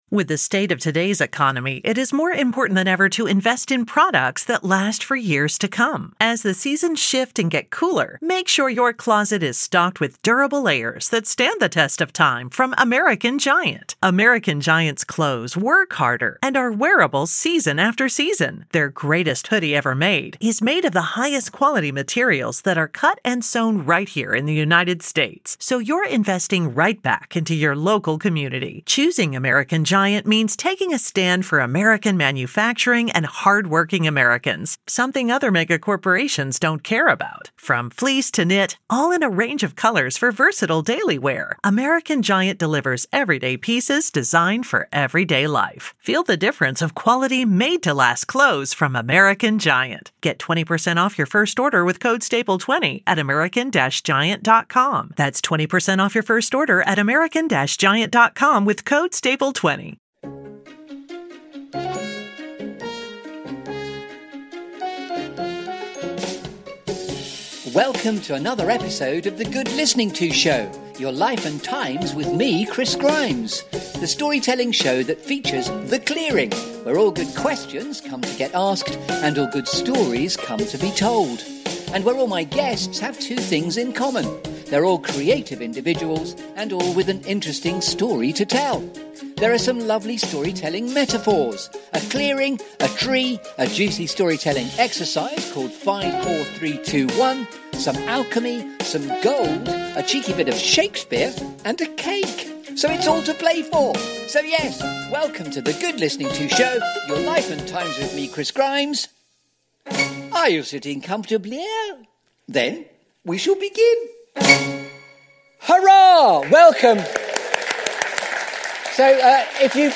Stories of Distinction and Genius LIVE with Robin Ince, Comedian, Writer, Broadcaster and Brian Cox Whisperer (!) LIVE from The Slapstick Festival, Bristol Old Vic.